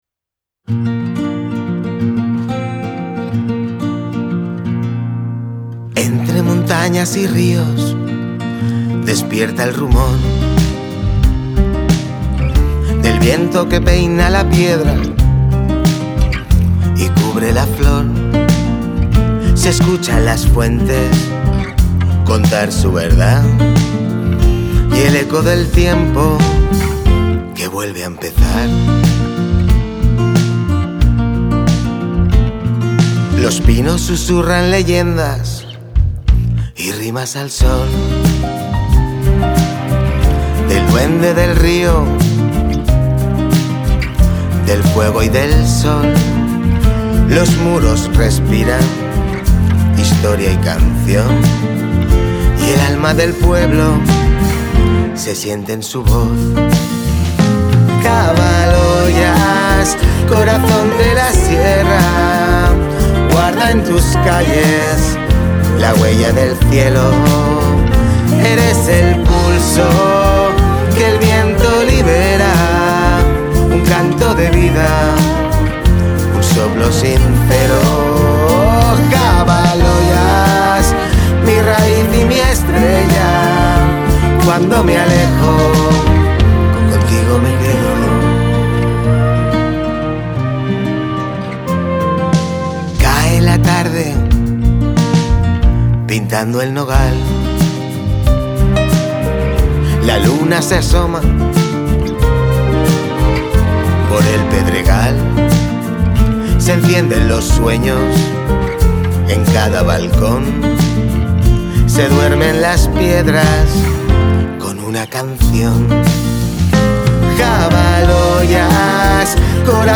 La canción ha sido grabada y mezclada en un estudio profesional como parte del premio.